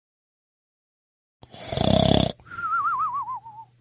snore.mp3